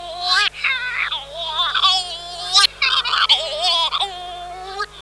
Scopoli’s Shearwater
Calonectris diomedea
Scopolis-Shearwater.mp3